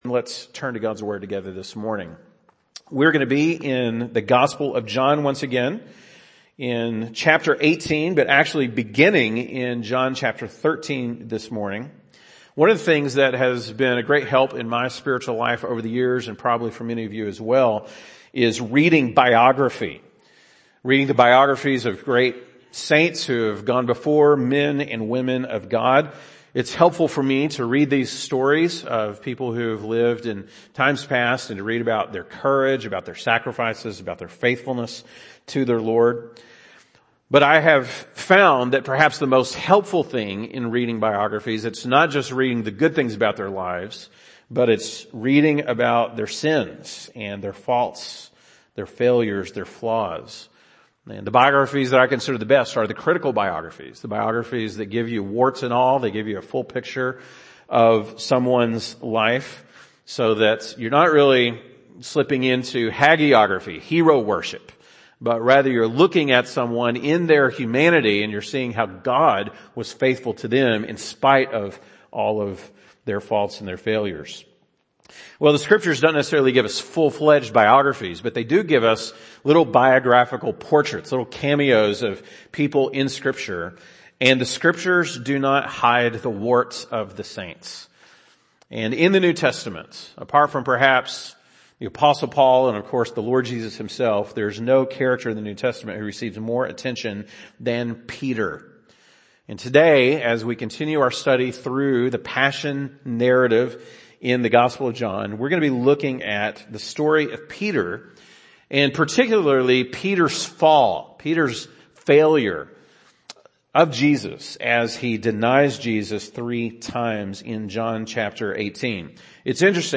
March 13, 2022 (Sunday Morning)